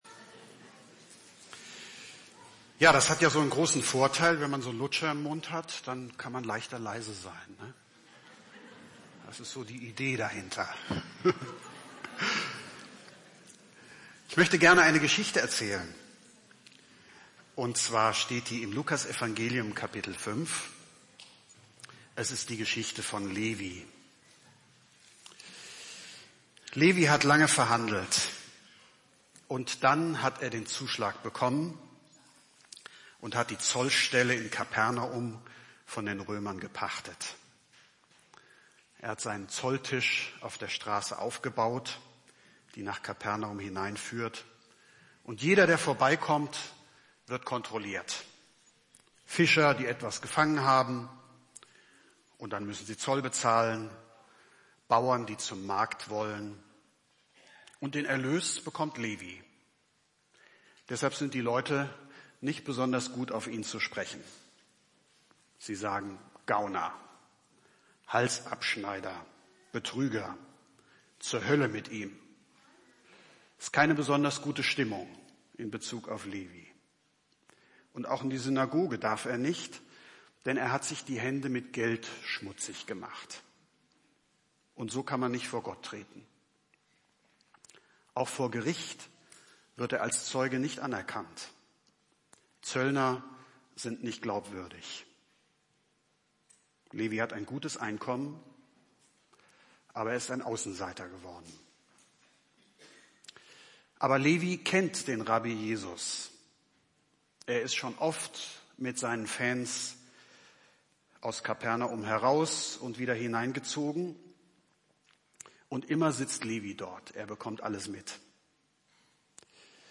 Die MP3.Datei ist aus dem vormittags Gottesdienst